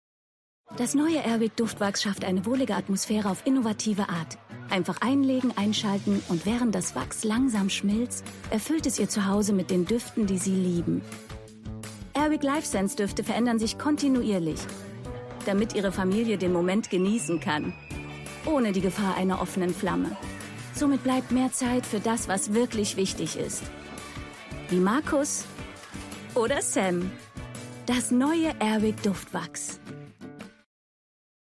Werbung Airwick Duftwax